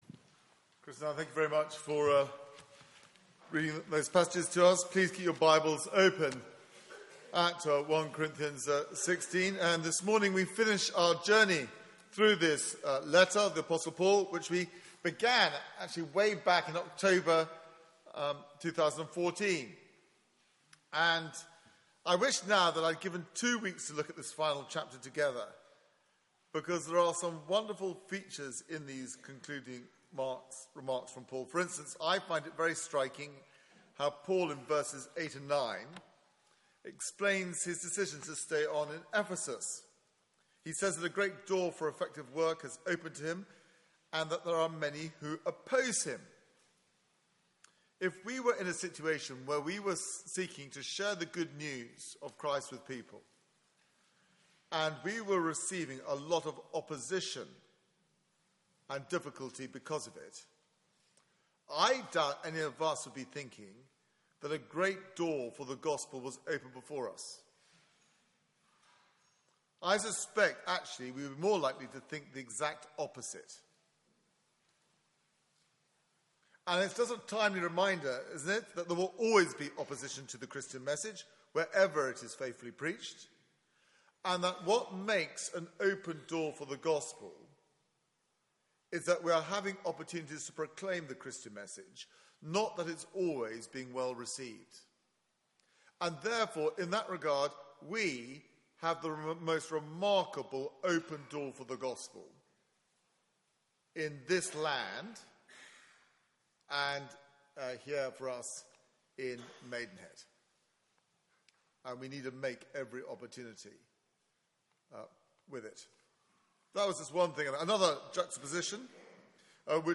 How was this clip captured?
Media for 9:15am Service on Sun 17th Apr 2016 09:15 Speaker